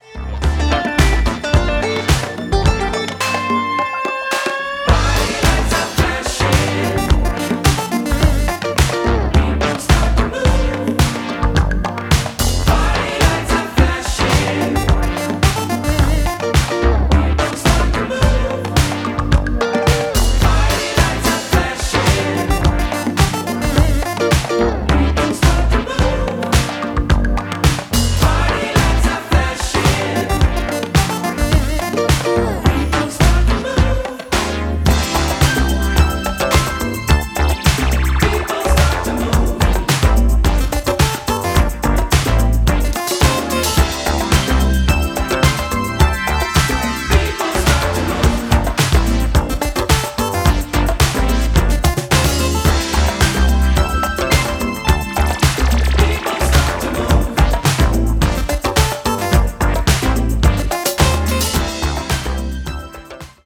and bolstered throughout by a dedicated brass section.